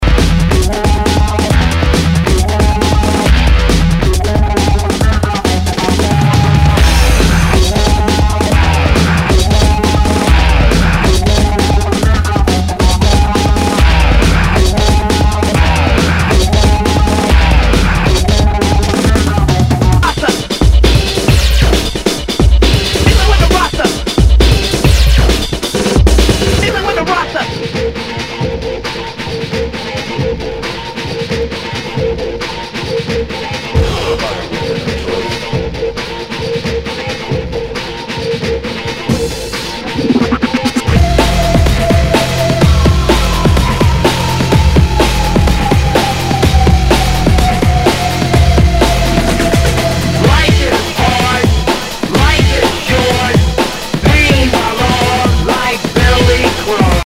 Nu- Jazz/BREAK BEATS
ナイス！ファンキー・ブレイクビーツ！
盤に傷あり、全体にチリノイズが入ります。